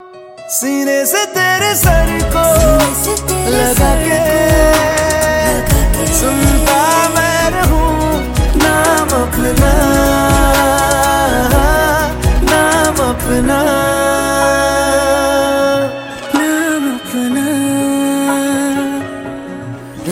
pine city waterfall sound effects free download